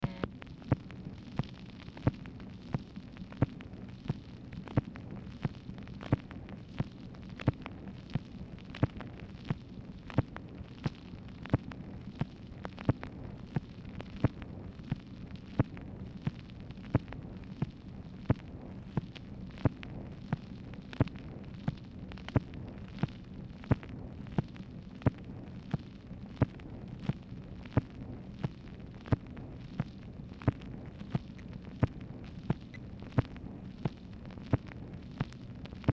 Telephone conversation # 8304, sound recording, MACHINE NOISE, 7/2/1965, time unknown | Discover LBJ
Format Dictation belt